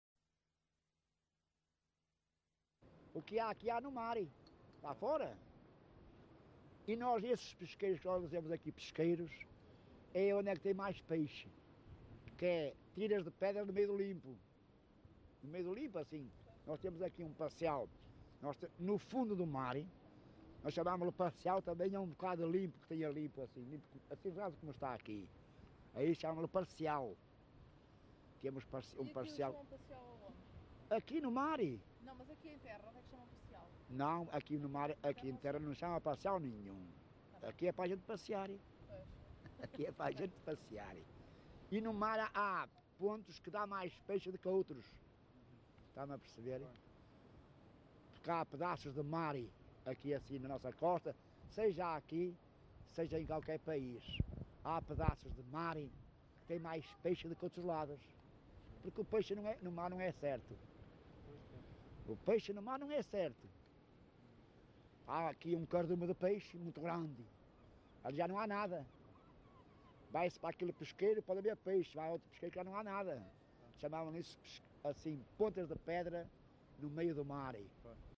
LocalidadeVila Praia de Âncora (Caminha, Viana do Castelo)